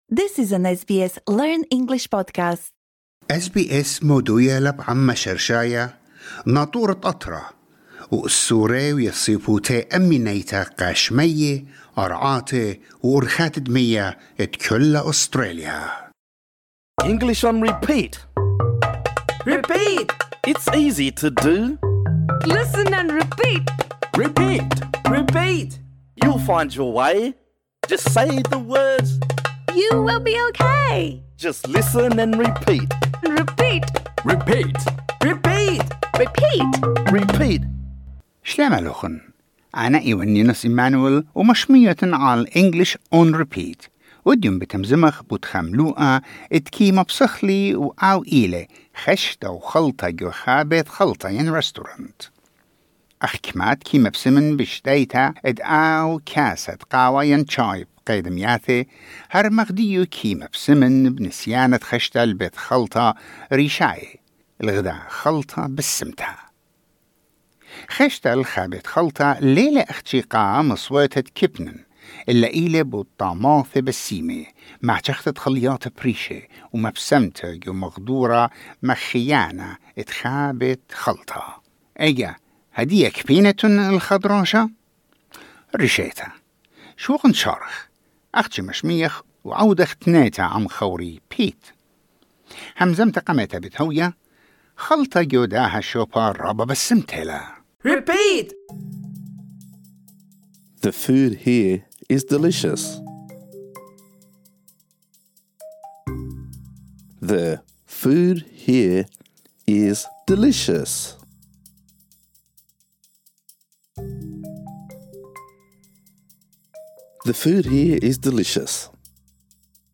This lesson is designed for easy-level learners. In this episode, we practise saying the following phrases: The food here is delicious.